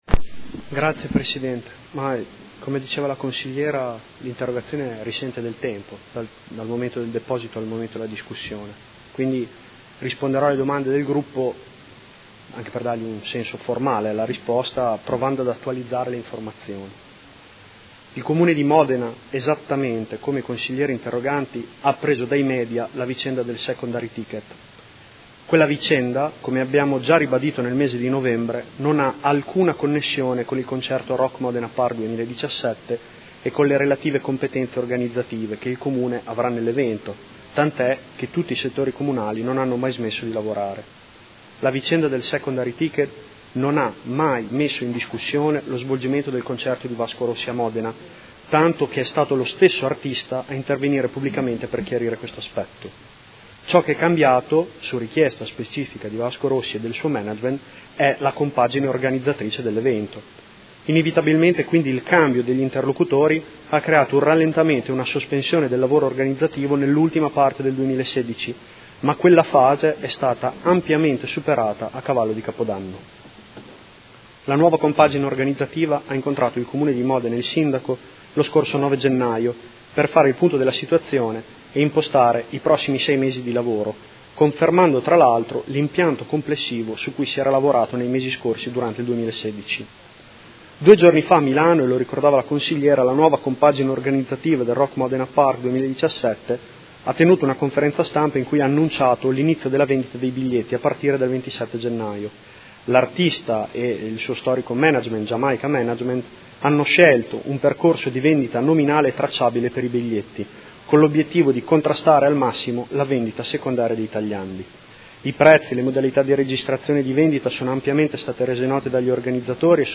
Seduta del 19/01/2017 Interrogazione dei Consiglieri Baracchi e Bortolamasi (P.D.) avente per oggetto: Sospensione concerto 1° luglio 2017 Vasco Rossi. Risponde l'Assessore